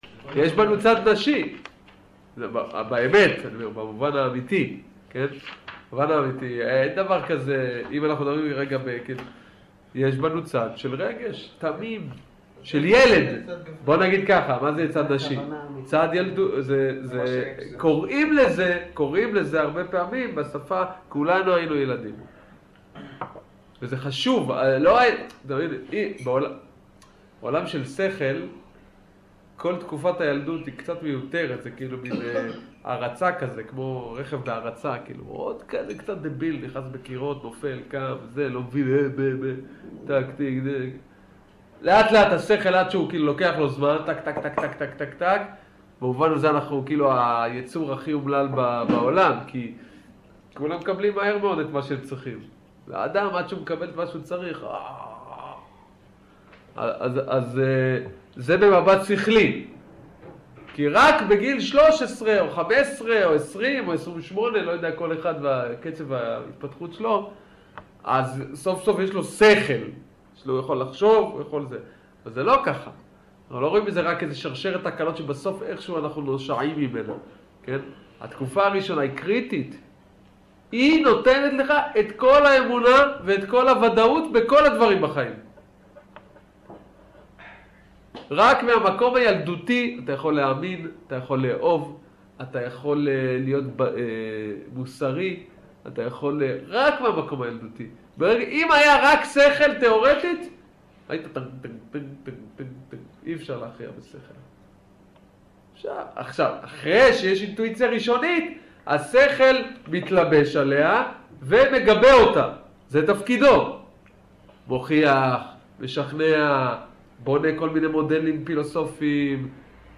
שיעור אמונה כג